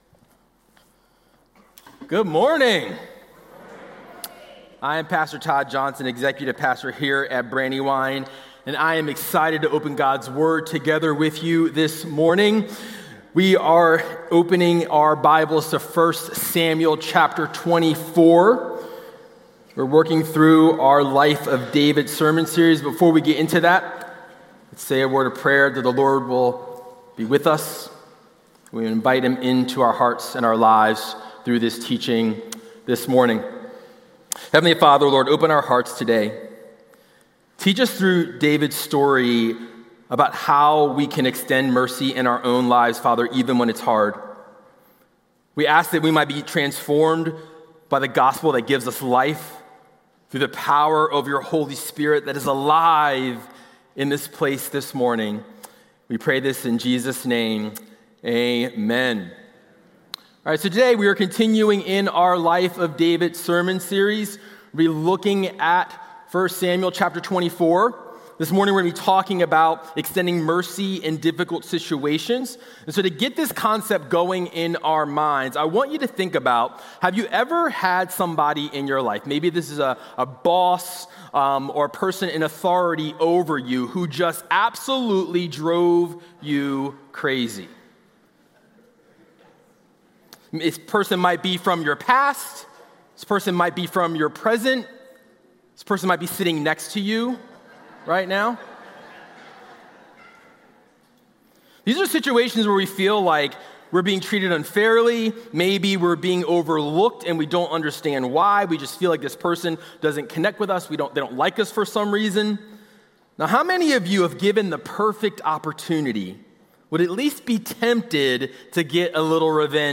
Listen to Sunday sermons from Brandywine Valley Church in Wilmington, Delaware.